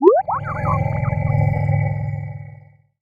bleep.wav